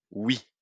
oui, loi, moyen, web, whisky wet